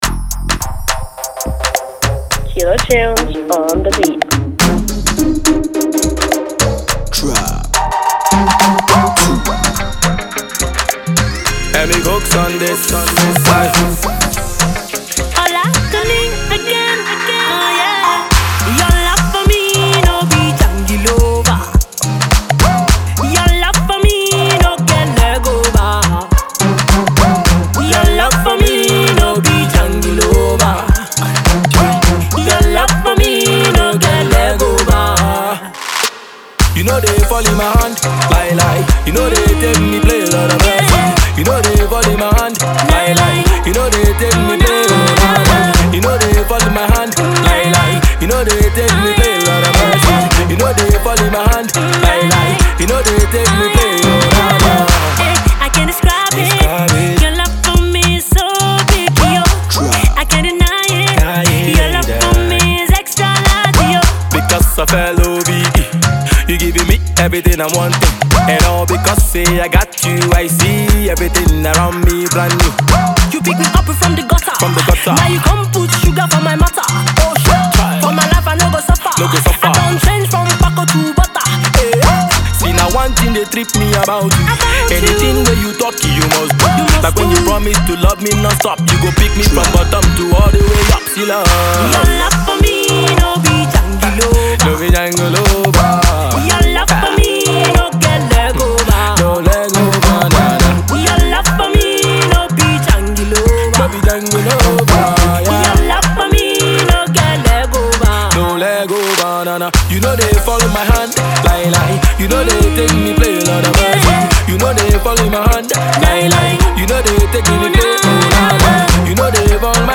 Street hop sound